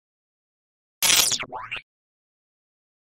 Sound Buttons: Sound Buttons View : Glitch_5
glitch_5.mp3